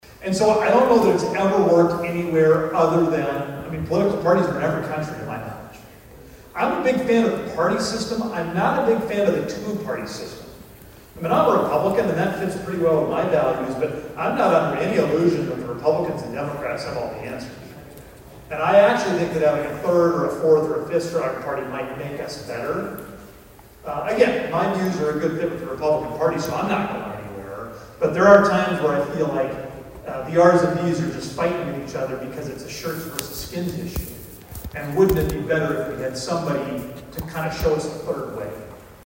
ABERDEEN, S.D.(HubCityRadio)- Monday kicked off the 82nd Boys State taking place at Northern State University in Aberdeen.